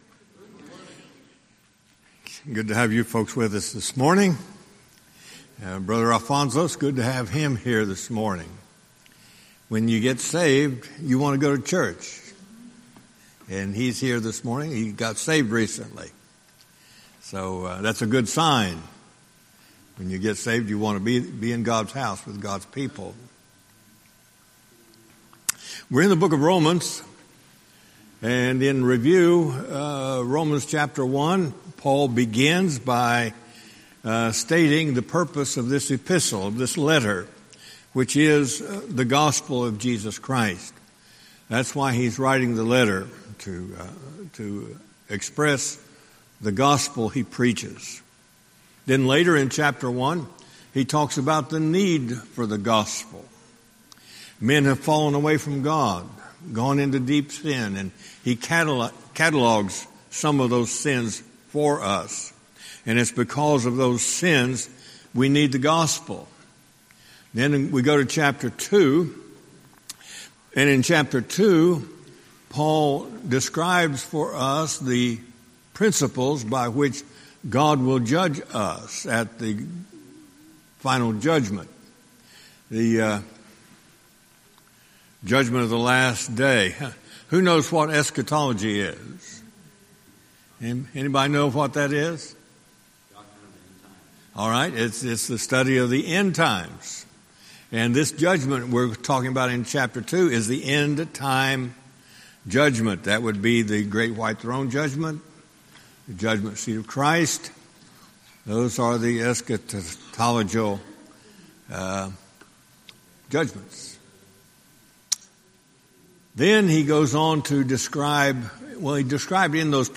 Sermons
Series: Guest Speaker